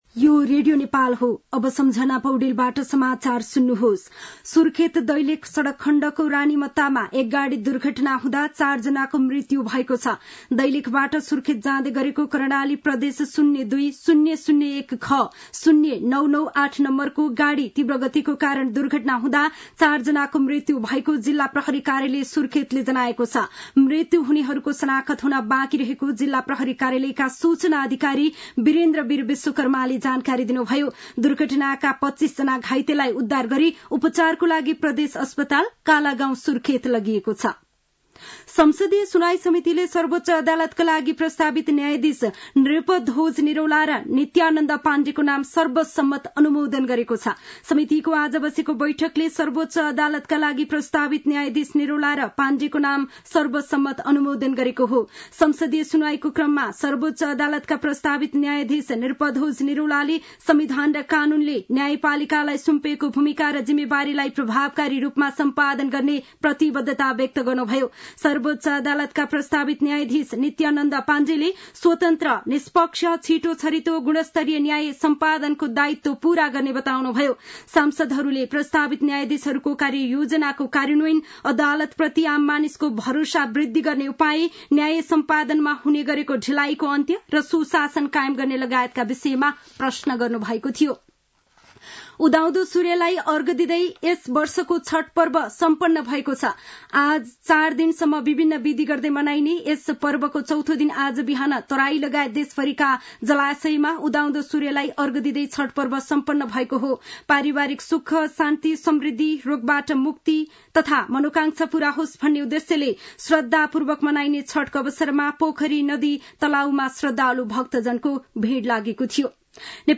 साँझ ५ बजेको नेपाली समाचार : २४ कार्तिक , २०८१